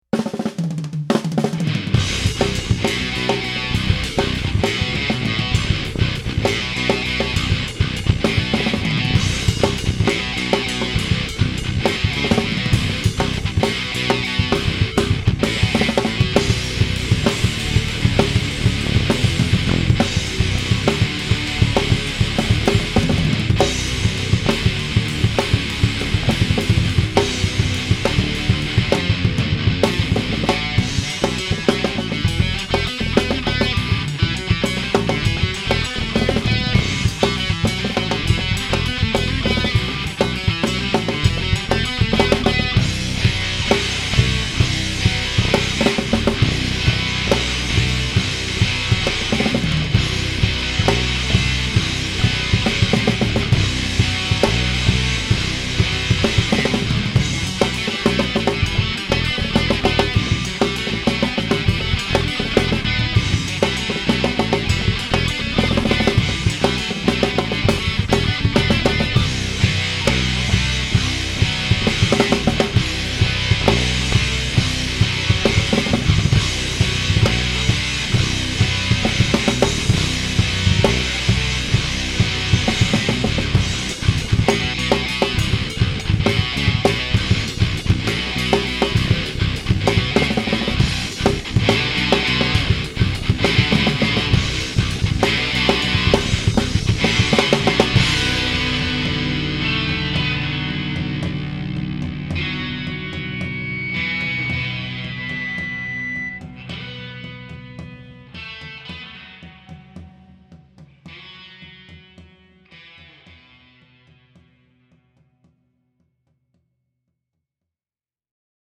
So, I have decided to offer another side of the song – here it is again, but without all those pesky lyrics and vocals getting in the way this time.